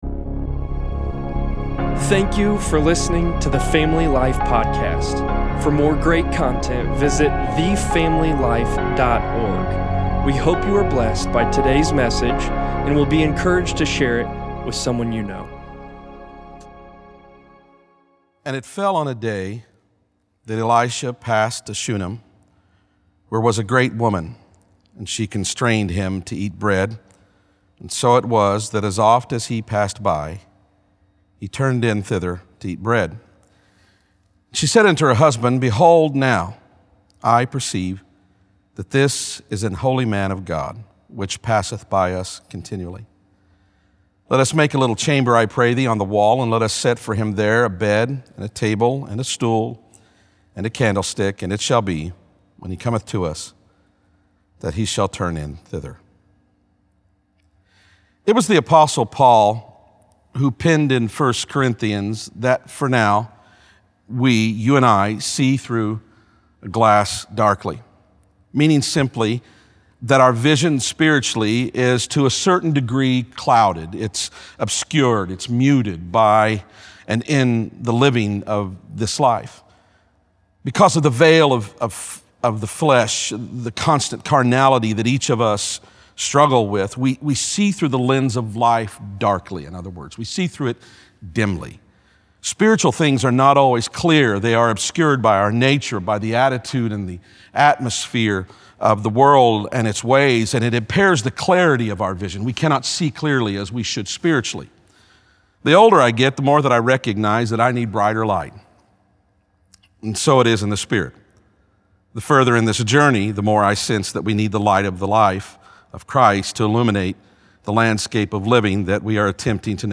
3.22.20_sermon_p.mp3